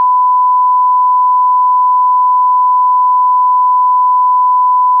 Includes a set of wav files of different frequencies, these are each loaded and then written to a temporary file, checking that the meta-data is correctly read and that the output matches the input. 2024-01-08 07:20:11 -07:00 215 KiB Raw Permalink History
tone_11025_stereo.wav